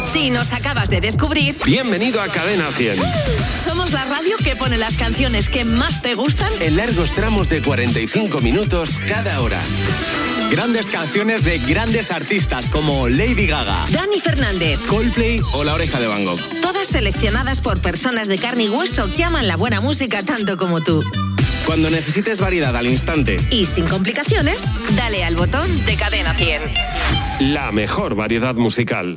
6a215a88d228ae7dea016fb20033e7852ac1e2c2.mp3 Títol Cadena 100 Emissora Cadena 100 Barcelona Cadena Cadena 100 Titularitat Privada estatal Descripció Promoció sobre els 45 minuts de música sense interrupció.